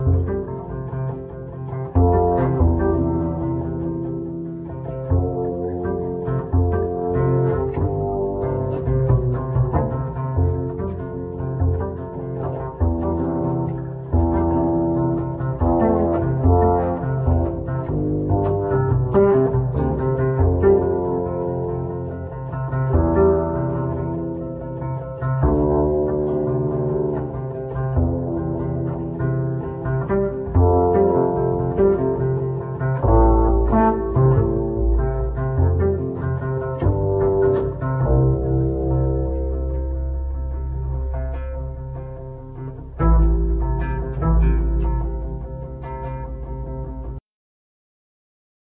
Double bass,Piano